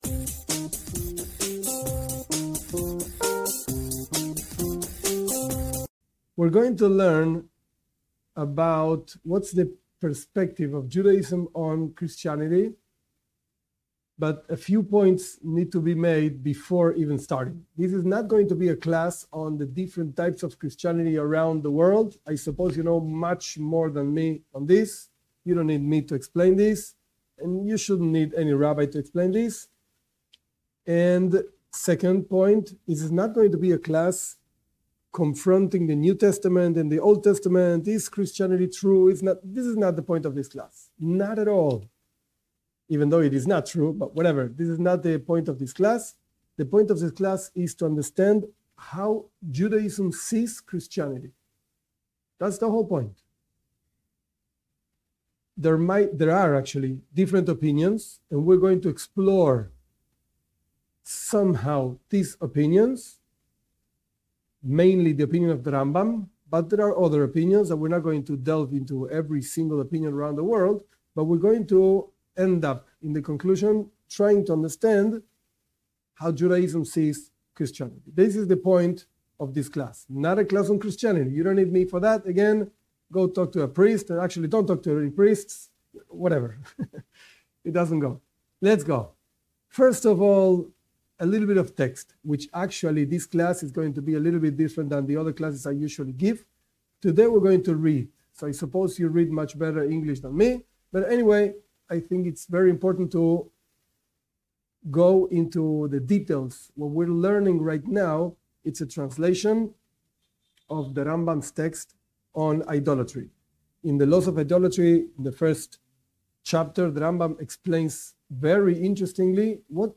This class analyzes the definition of idolatry according to Judaism and the different perspectives regarding how does that definition applies (or not) to Christianity.